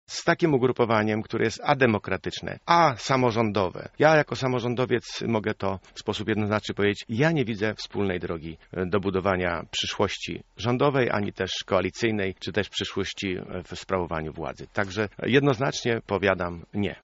Poseł PSL wykluczył możliwość stworzenia koalicji z Prawem i Sprawiedliwością. Taka deklaracja padła w rozmowie z Janem Łopatą w Porannej Rozmowie Radia Centrum.